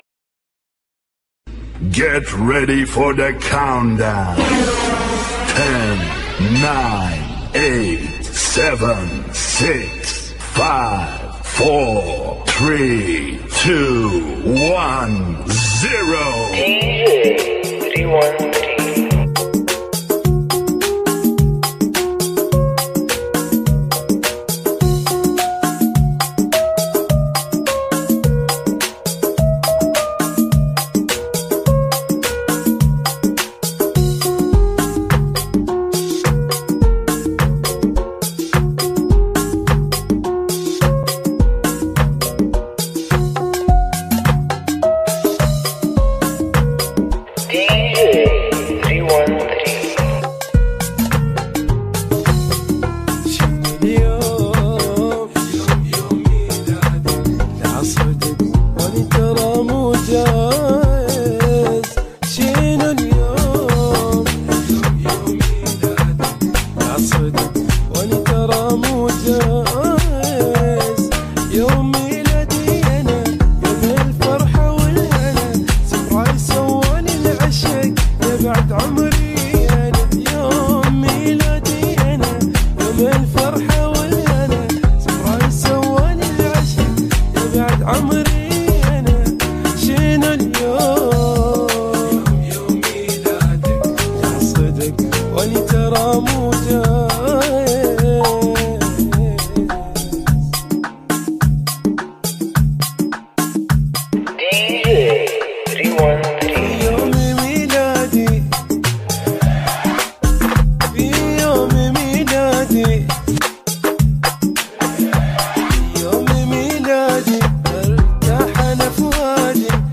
ريمگـس